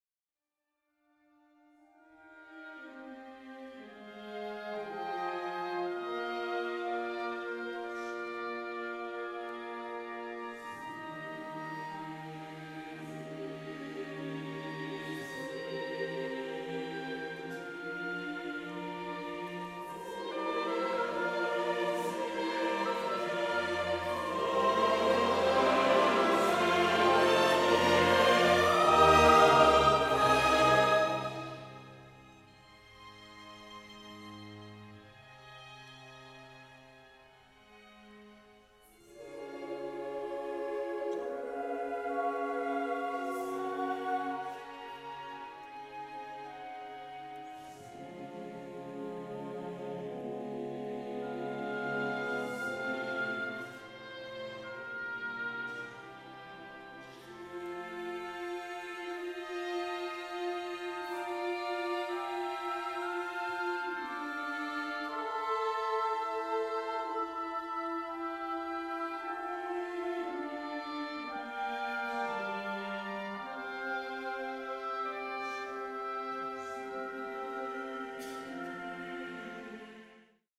Beigazolódott a sejtésem, hiszen itt az egész művet nyitó "selig sind" motívum két változatával, és az előbb megfigyelt alt-tenor idézettel találkozhattunk.